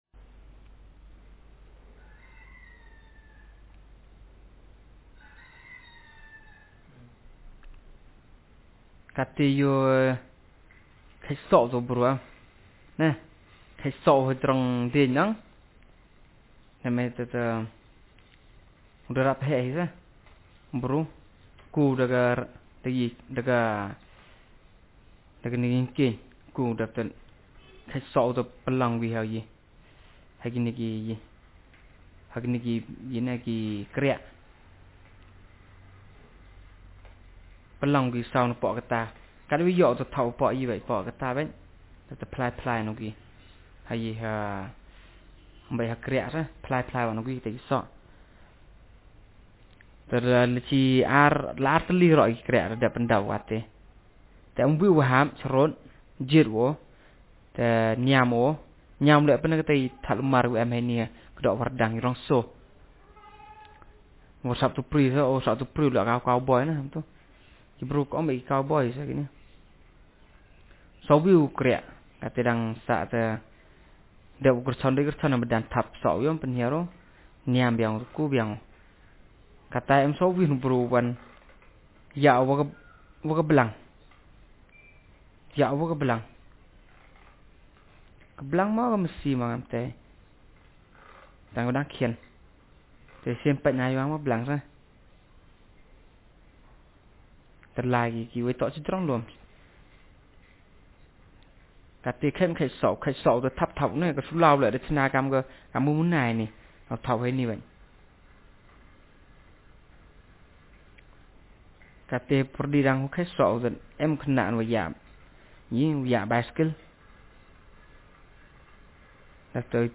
Speaker sexm
Text genrestimulus retelling